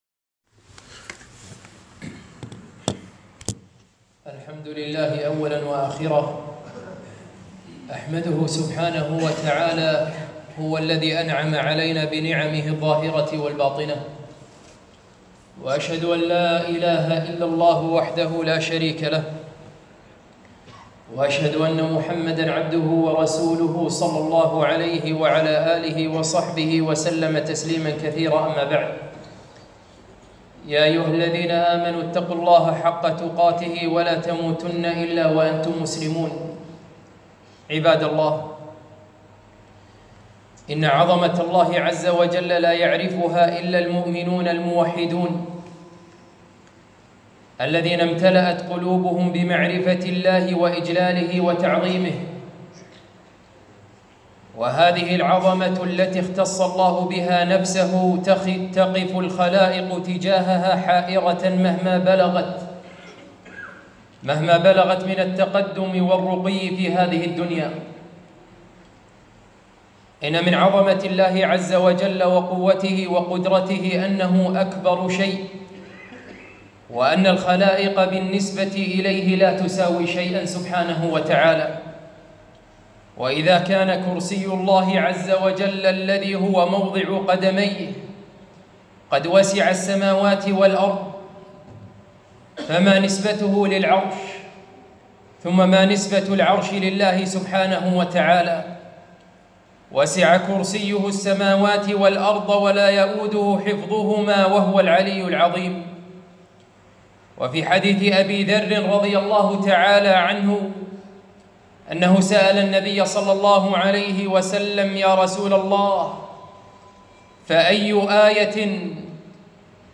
خطبة - قدرة الله في إرسال المطر